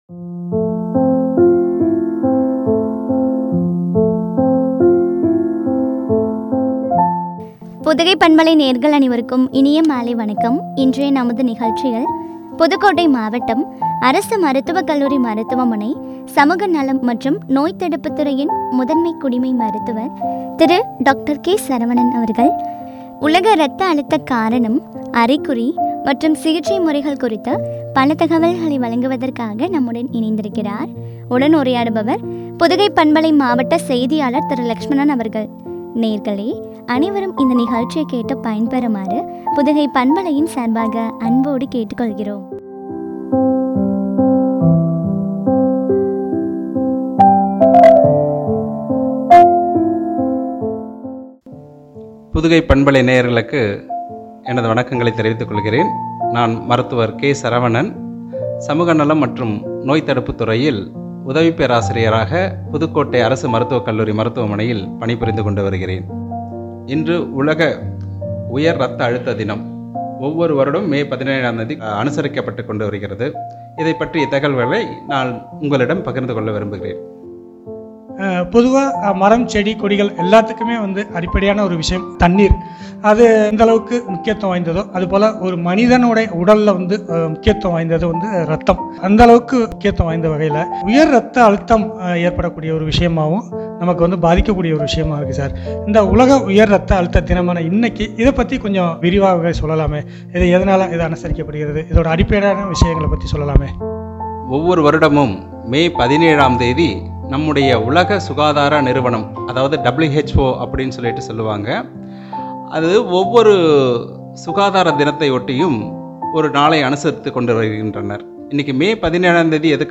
உயர் இரத்த அழுத்தம்- காரணம் ,அறிகுறி மற்றும் சிகிச்சை முறைகள் பற்றிய உரையாடல்.